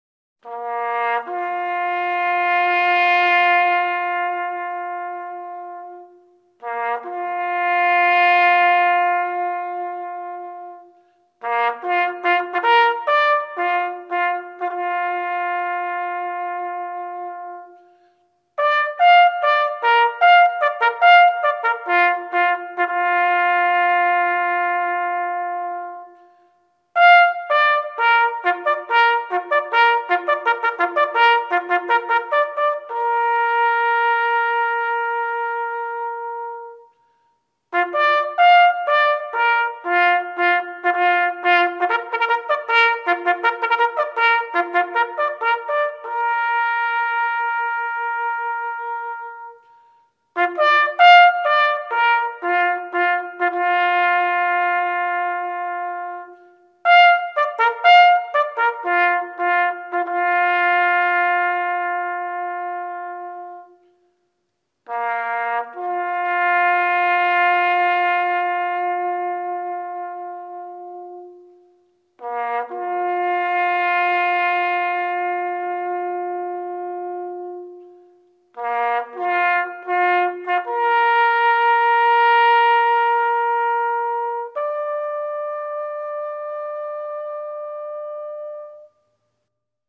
With ANZAC Day  fast approaching once again, DVA has made available a DVD which contains audio tracks performed by the Royal Military College Band at Duntroon which can be used at commemorative ceremonies.